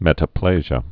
(mĕtə-plāzhə)